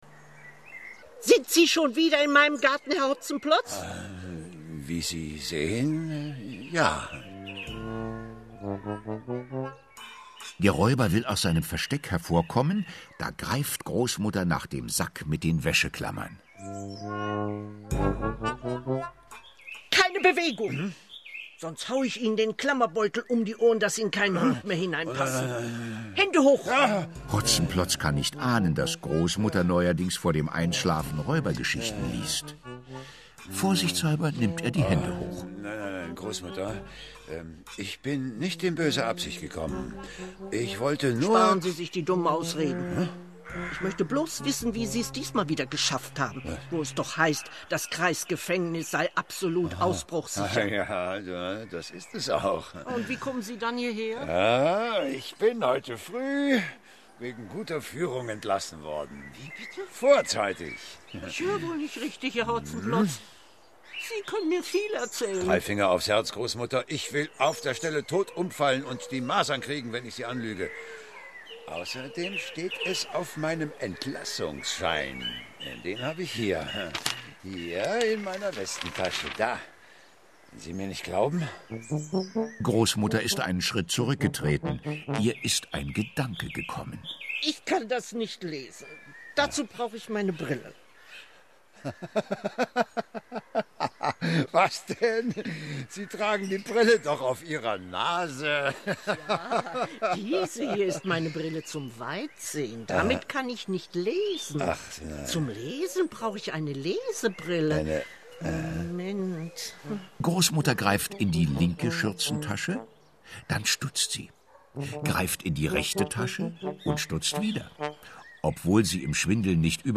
Hörspiel für Kinder (2 CDs)
Michael Mendl, Dustin Semmelrogge (Sprecher)
Die Räubergeschichte aus der Feder Otfried Preußlers in einer Hörspiel-Neuinszenierung des WDR mit einem herrlich brummigen Michael Mendl als Mann mit den sieben Messern.